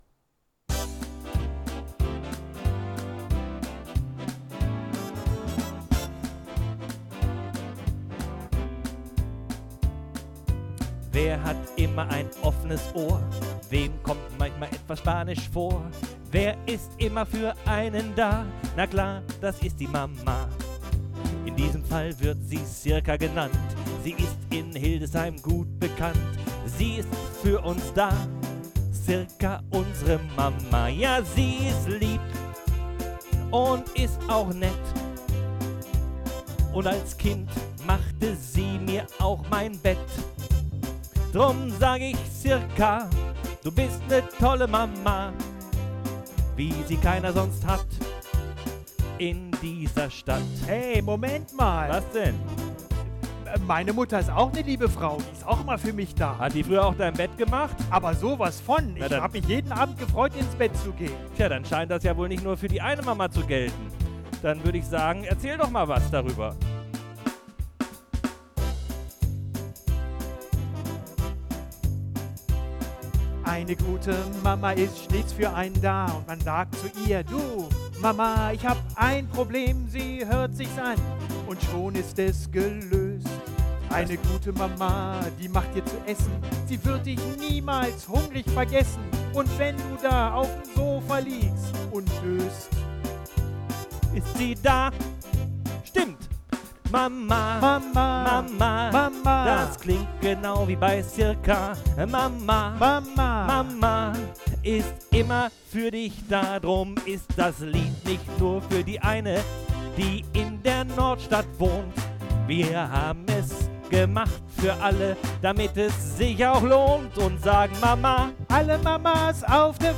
Getragen oder beschwingt?